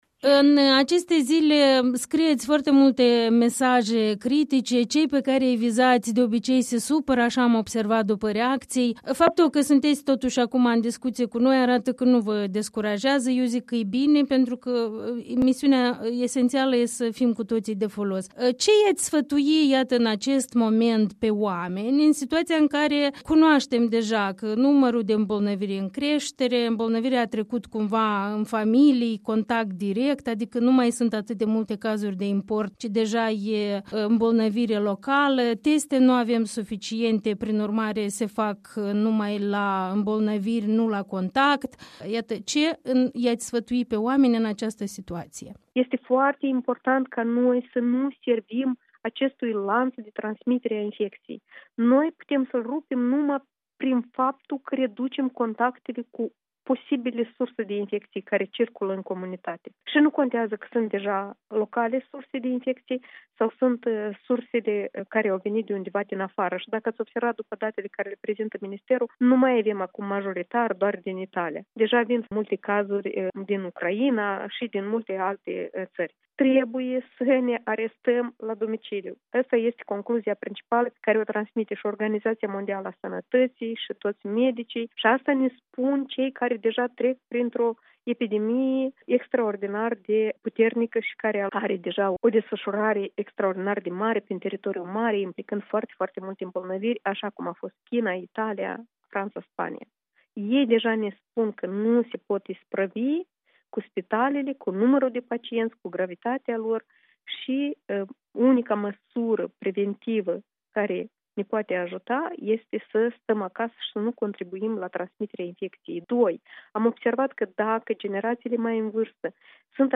Interviul matinal cu Ana Nemerenco, fosta ministră a sănătății